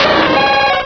Cri de Suicune dans Pokémon Rubis et Saphir.